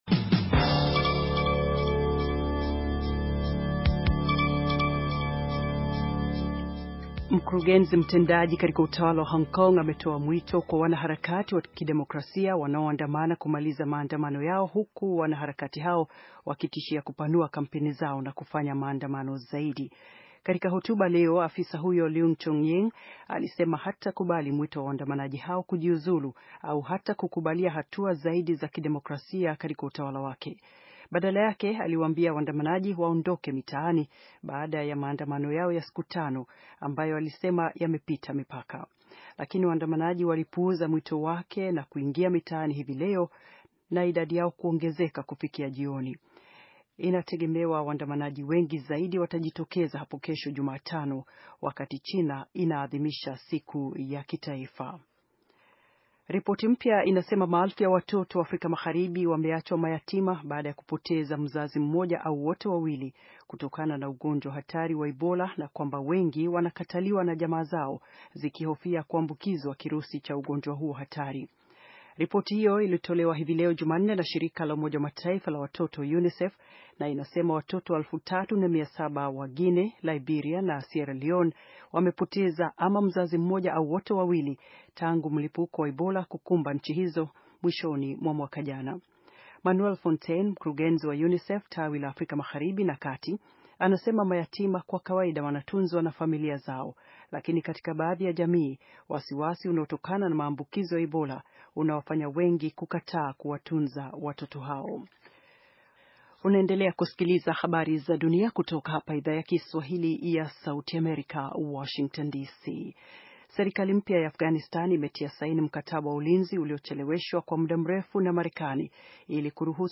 Taarifa ya habari - 5:54